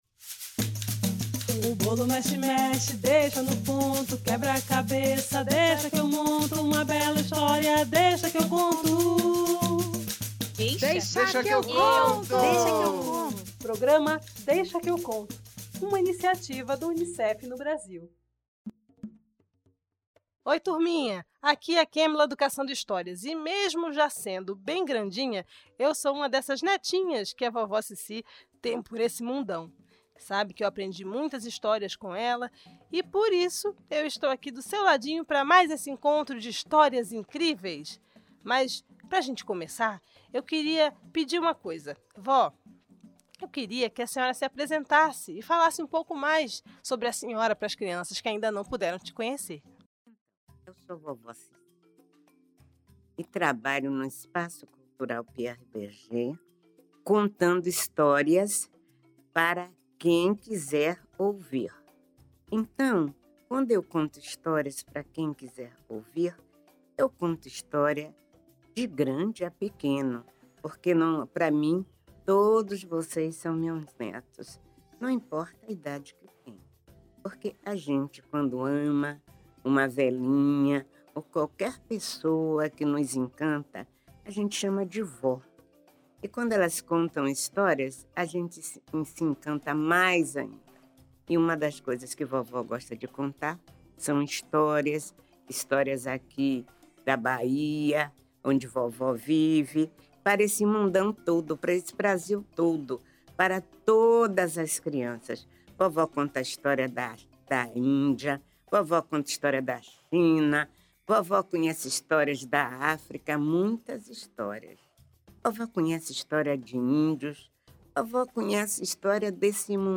cantigas, histórias, boas conversas e o convite para brincar com um saquinho mágico